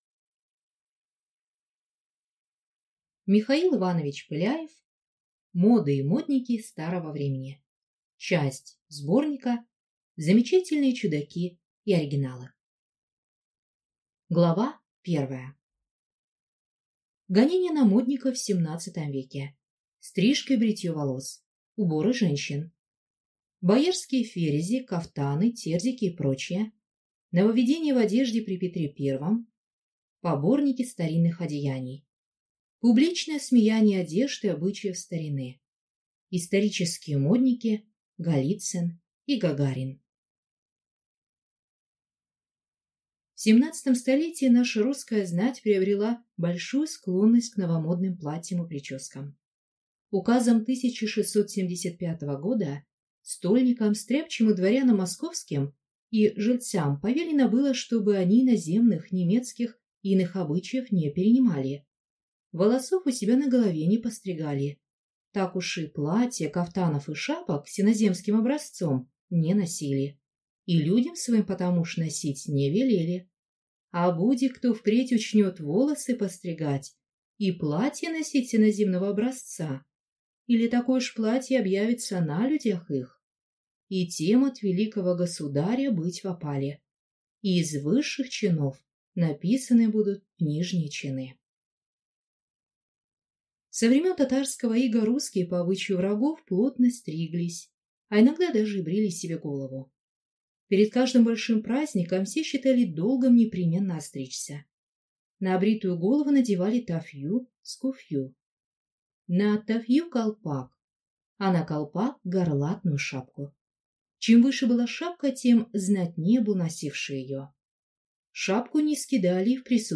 Аудиокнига Моды и модники старого времени | Библиотека аудиокниг